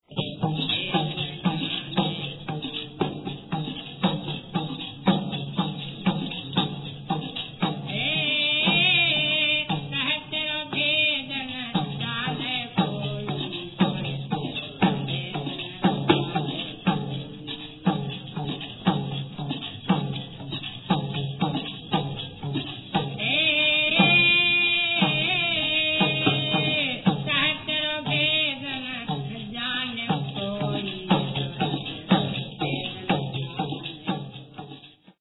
Monk - Bhajan - 5:48